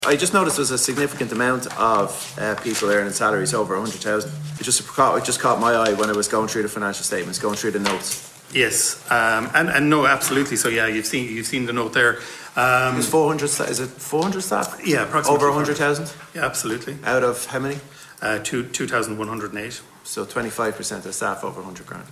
Officials from Uisce Eireann are appearing before the Public Accounts Committee, outlining the company’s financial statements for 2024.